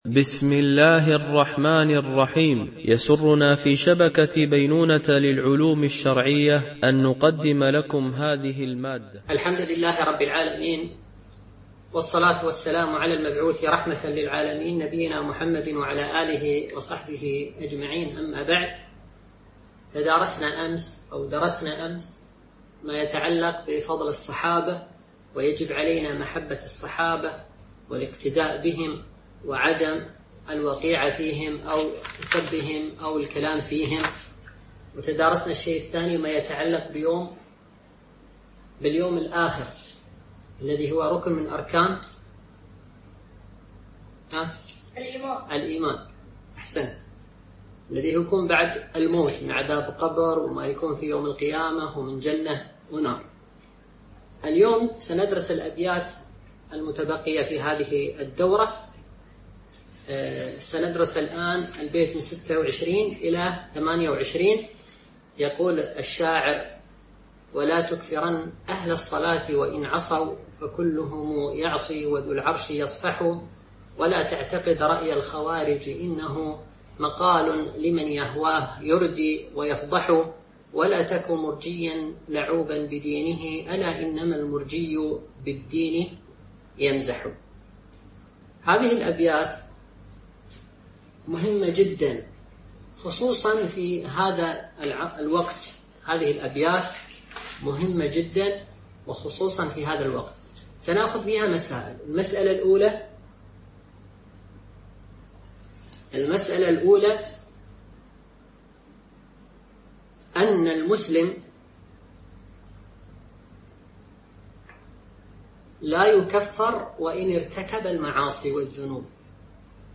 دورة علمية عن بعد